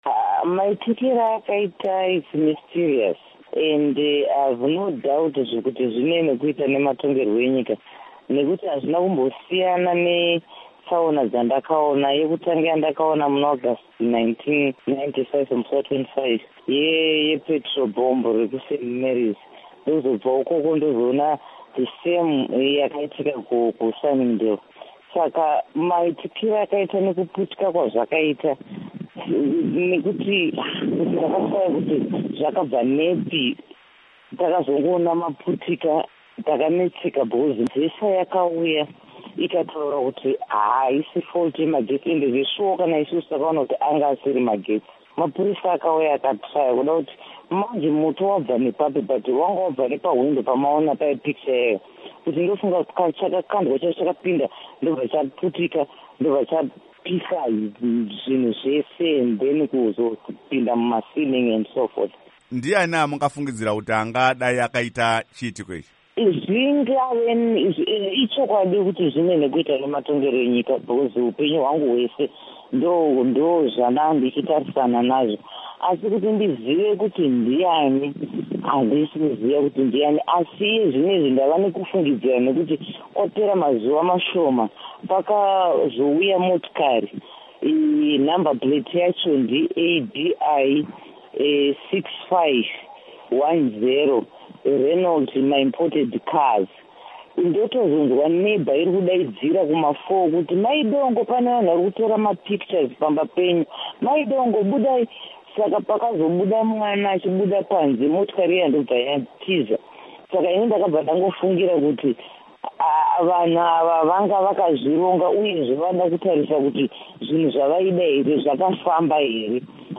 Hurukuro naAmai Margaret Dongo